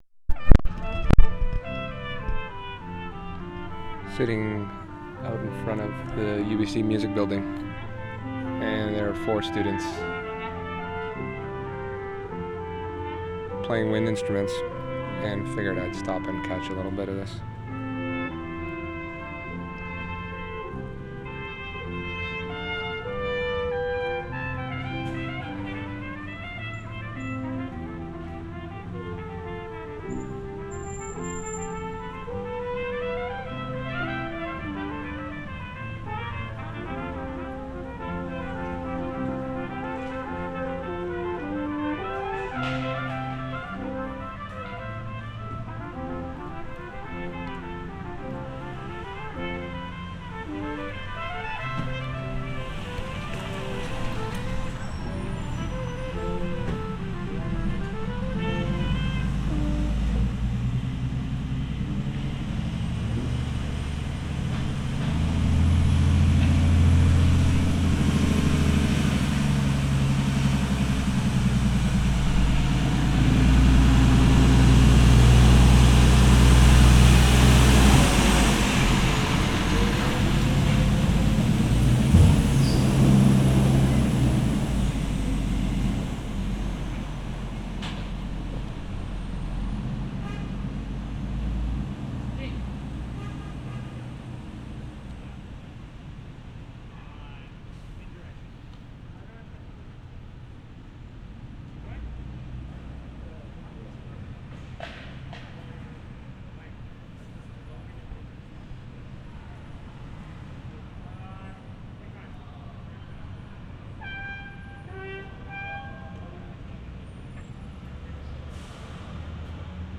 Outside the Music Building, 3:38
10. Track ID @ intro, brass quartet outside the music building holding a practice, garbage truck passes at 0:55, horn at 1:30, they begin the piece again at 2:50, end at 3:30.